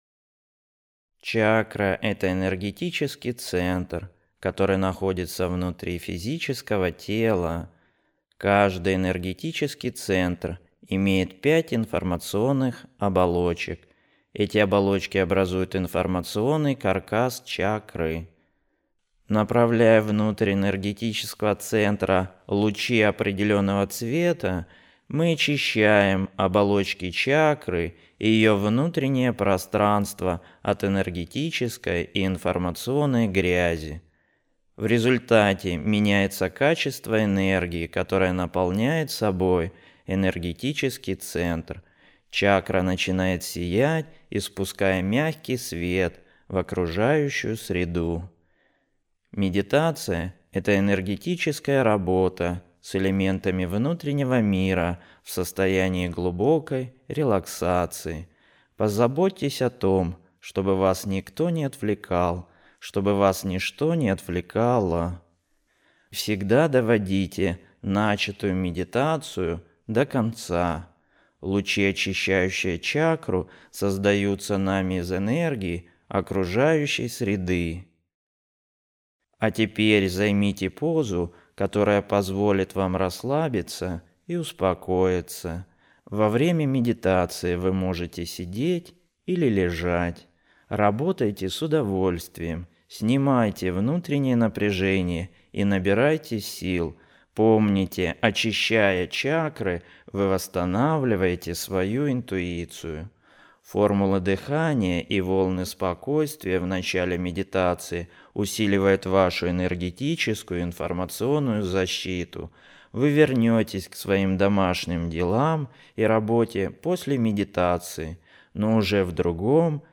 Медитация
Медитативная музыка